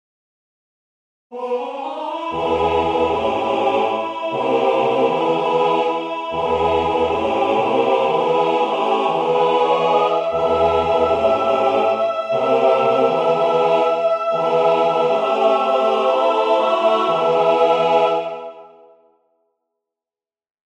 Key written in: E Major
How many parts: 4
Type: Barbershop
All Parts mix: